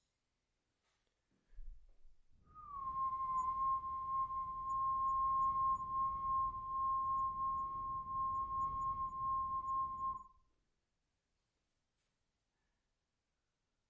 我的声音 " 哨子
描述：吹口哨的单一音符
标签： 吹口哨 吹口哨
声道立体声